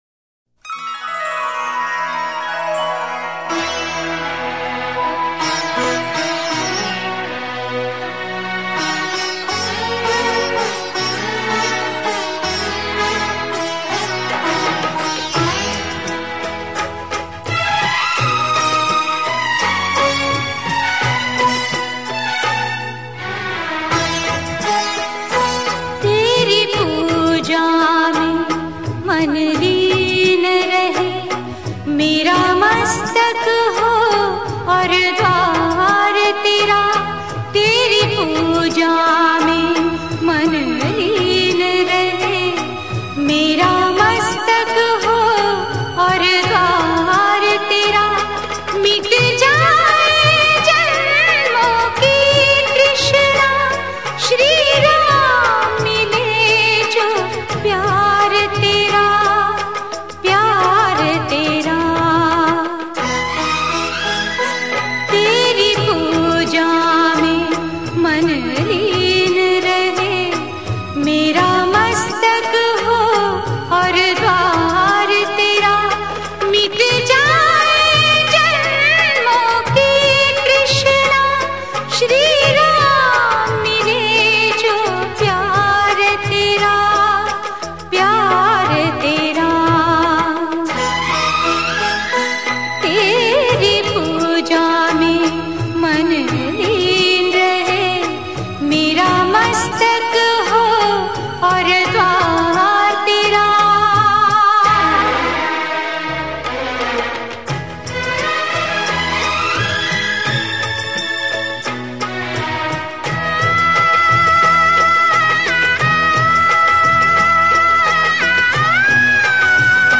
Shree Ram Bhajans